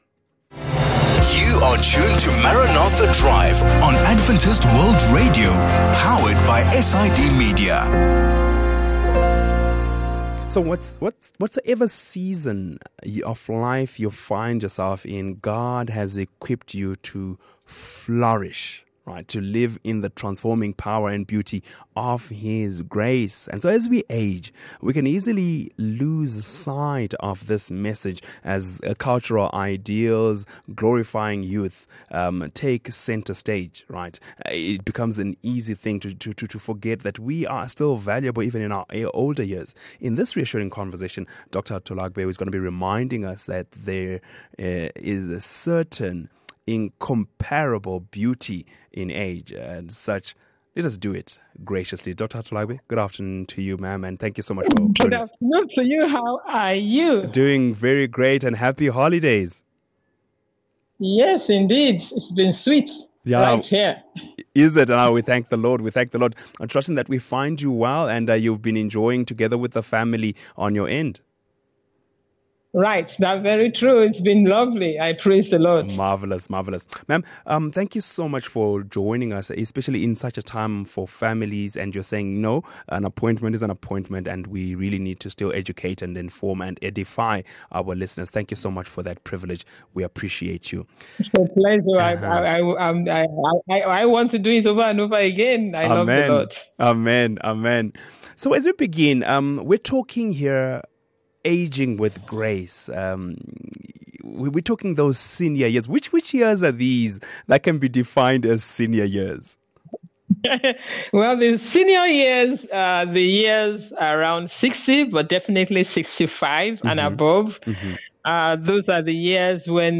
Whatever season of life you’re in, God has equipped you to flourish—to live in the transforming power and beauty of his grace. As we age, we can easily lose sight of this message as cultural ideals glorifying youth take center stage. In this reassuring conversation